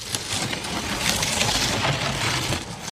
catapult.ogg